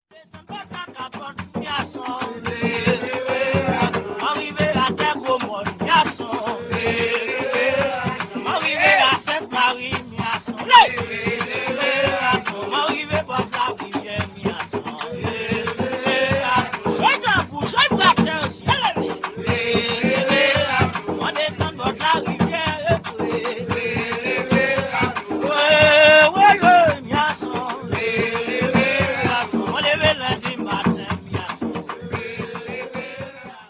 play Sound ClipNo such problems of accessibility attend the drum-accompanied work songs; as this clip shows, they are extremely hot and exciting music, so much so, indeed, that they represent something of a puzzle.
But if what’s required for hauling logs and boats is ‘a long pull and a strong pull,’ the rhythm here is surely not being given to the workers by the frenetic sound of the tanbou bèlè and ti bwa.  I’m forced to conclude that the work rhythm is in the song - that it is, indeed, a work song - and that the drummers are supplying something else.